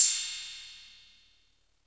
• Ride Cymbal Audio Clip A# Key 07.wav
Royality free ride cymbal single shot tuned to the A# note. Loudest frequency: 8514Hz
ride-cymbal-audio-clip-a-sharp-key-07-p29.wav